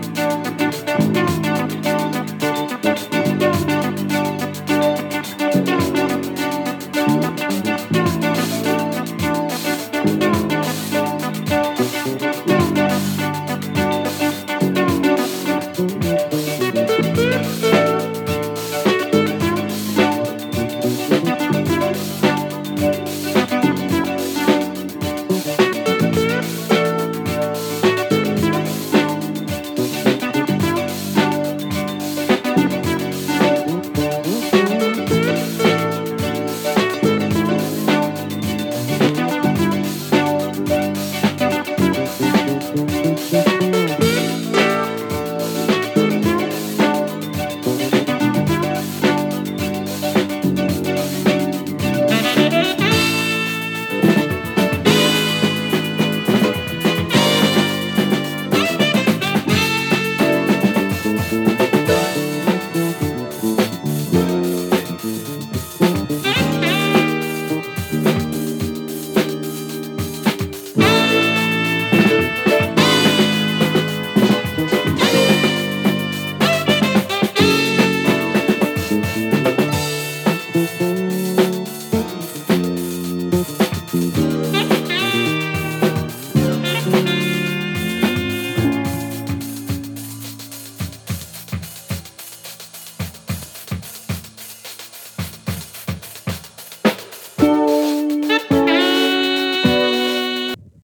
東欧ジャズ 疾走 コズミック フュージョン